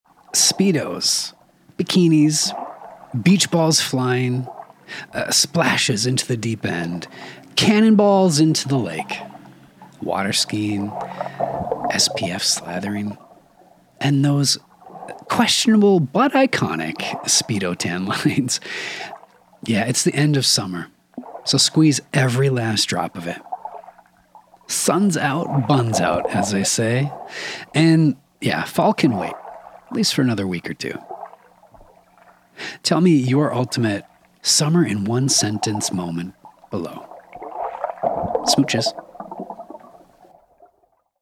A cheeky little end-of-summer monologue to clean the palate.